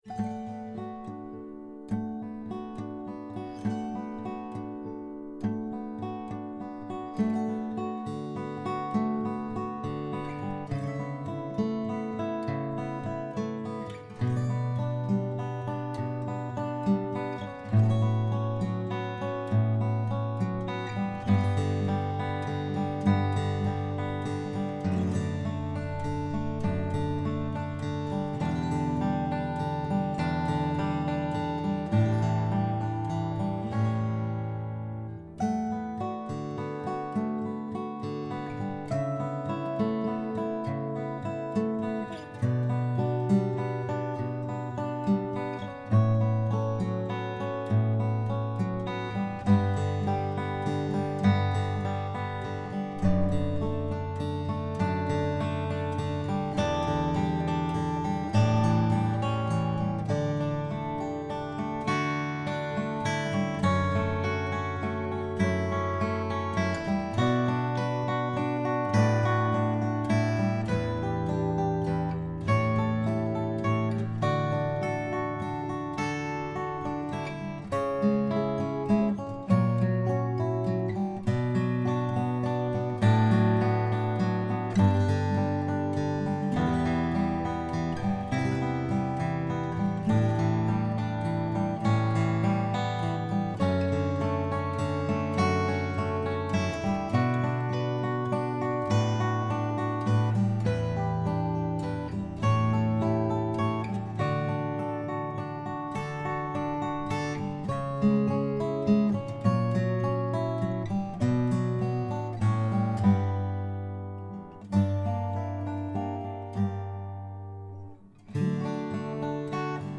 Below are new guitar recordings for my songs “Someone to Love You” and “How We Don’t Care.”